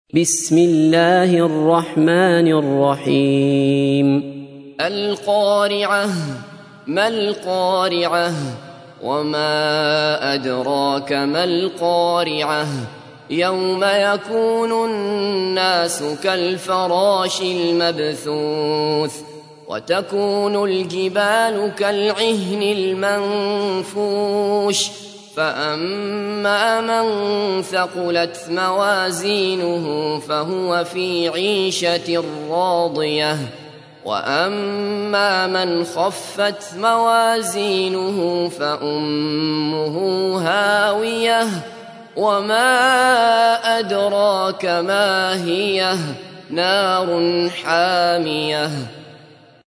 تحميل : 101. سورة القارعة / القارئ عبد الله بصفر / القرآن الكريم / موقع يا حسين